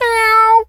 pgs/Assets/Audio/Animal_Impersonations/cat_meow_02.wav at master
cat_meow_02.wav